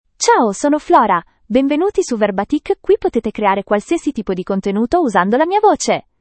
Flora — Female Italian (Italy) AI Voice | TTS, Voice Cloning & Video | Verbatik AI
Flora is a female AI voice for Italian (Italy).
Voice sample
Listen to Flora's female Italian voice.
Female
Flora delivers clear pronunciation with authentic Italy Italian intonation, making your content sound professionally produced.